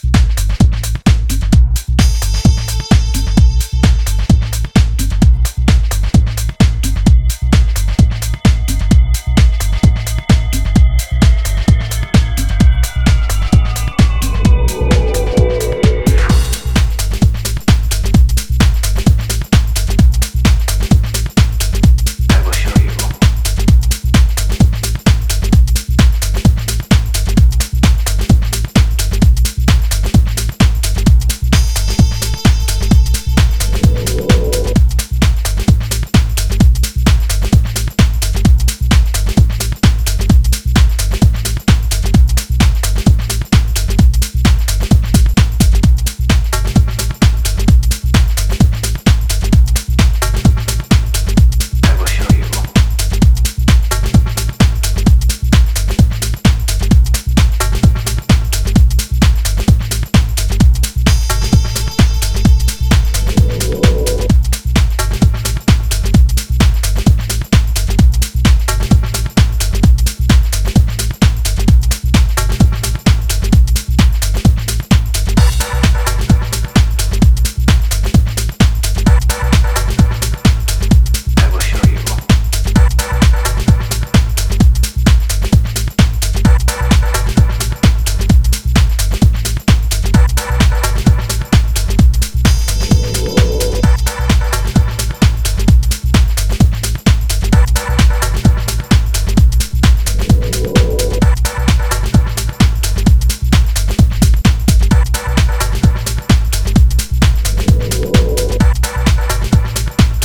a raw, late-night three-tracker oozing groove and attitude.
tech house